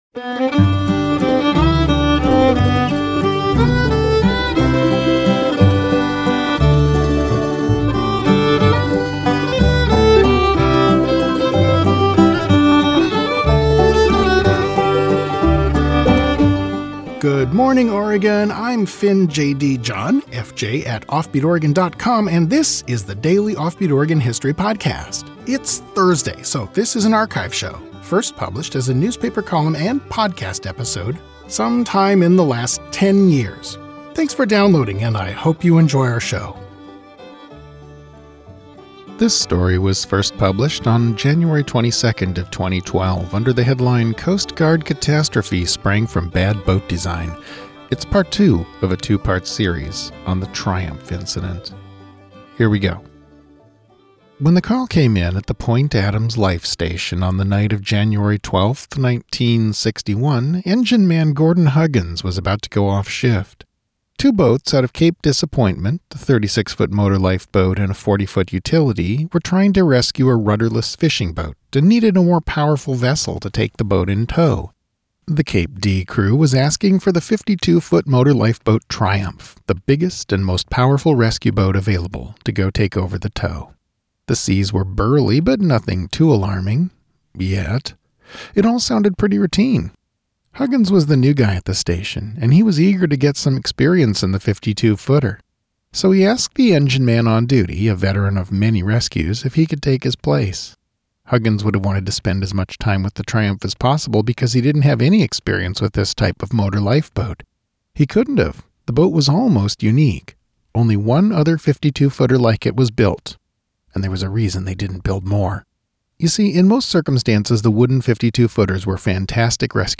Audio verison of this article